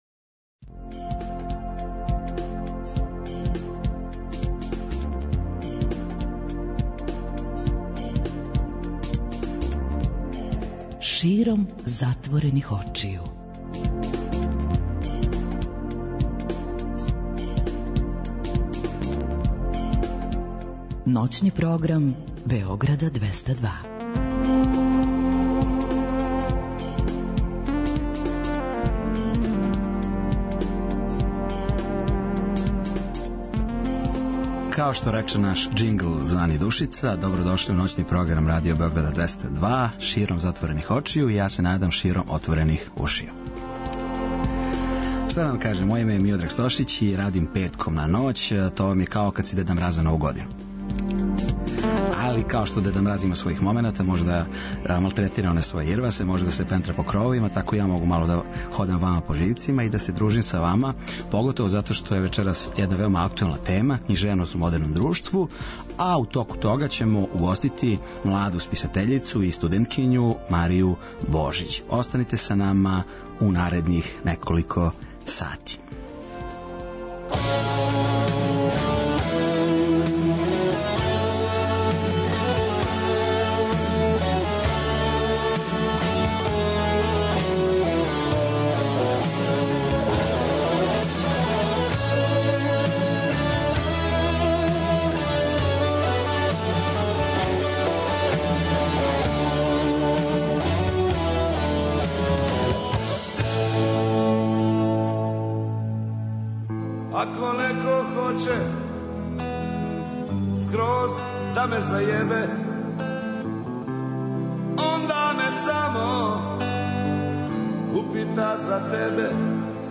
У току емисије биће подељено неколико награда слушаоцима који се јаве да причају са нама.